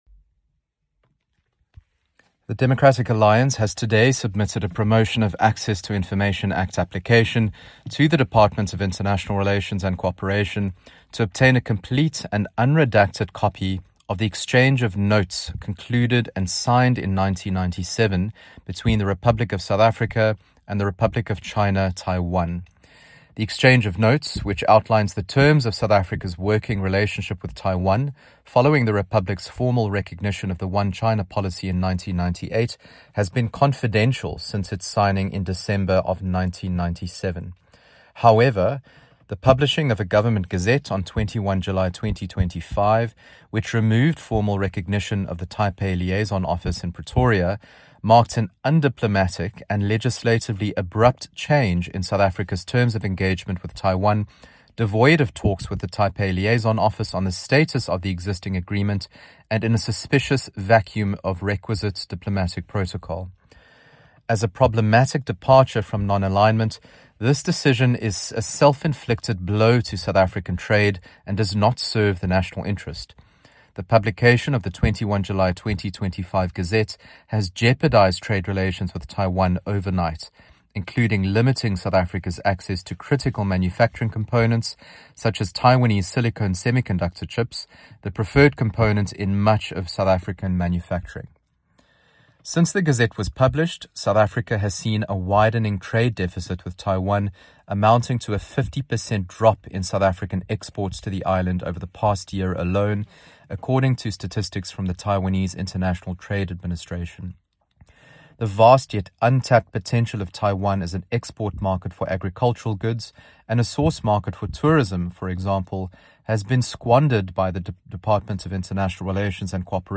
Attention Broadcasters: Sound by Ryan Smith MP in